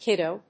音節kid・do 発音記号・読み方
/kídoʊ(米国英語), kídəʊ(英国英語)/